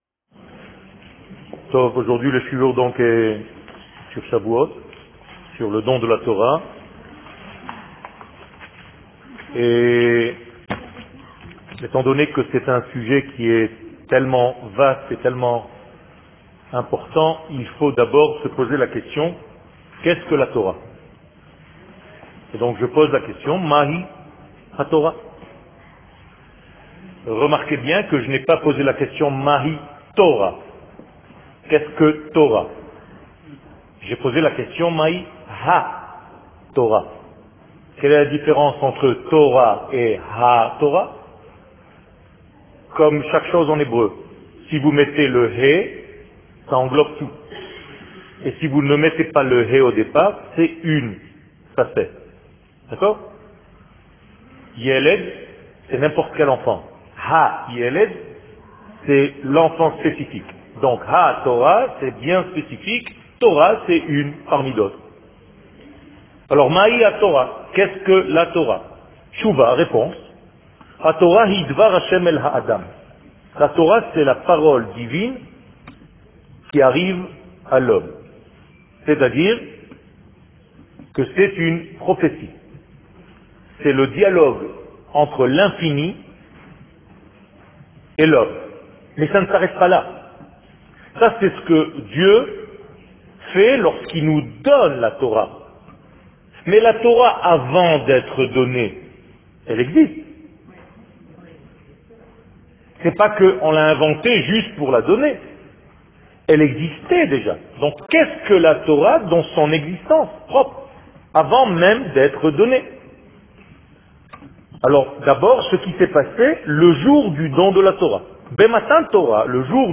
Fetes/Calendrier שיעור מ 28 מאי 2017 55MIN הורדה בקובץ אודיו MP3 (9.5 Mo) הורדה בקובץ אודיו M4A (6.58 Mo) TAGS : Chavouot Torah et identite d'Israel שיעורים קצרים